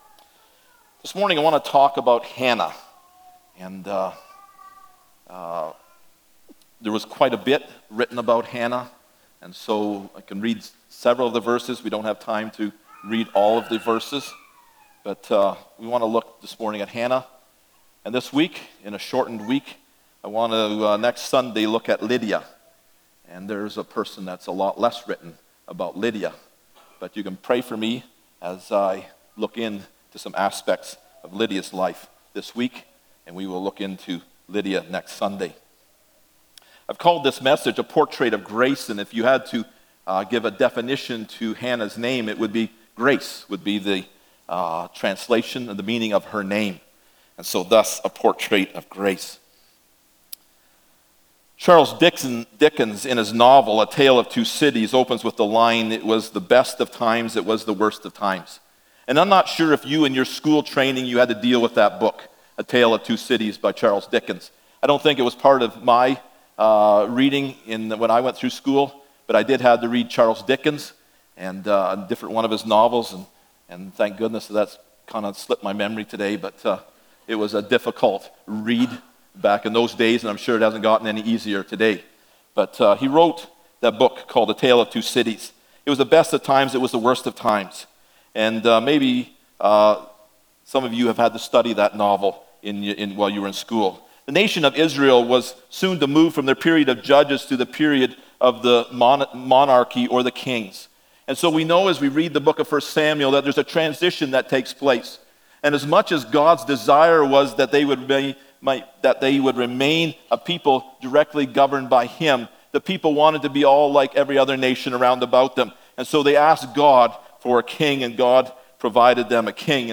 Characters of the Bible Passage: 1 Samuel 1:1-6, 19-23 Service Type: Sunday Morning « Deborah